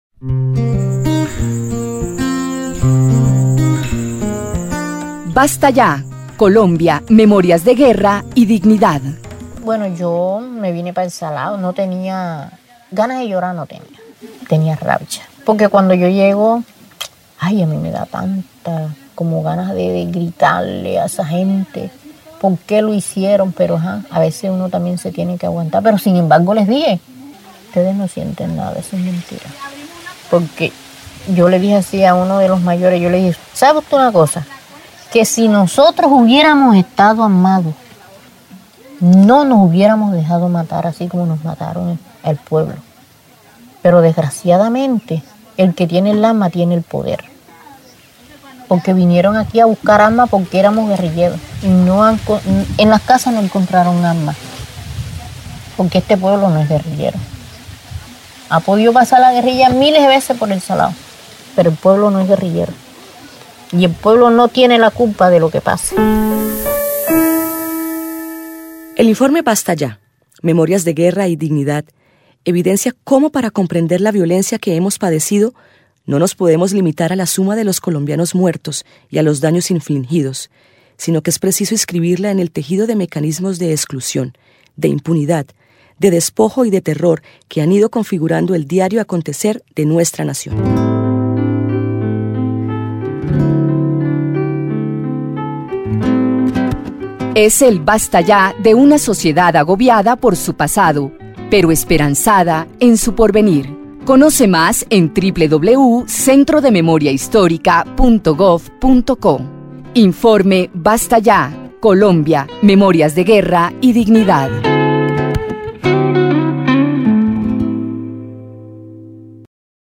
Narrativas testimoniales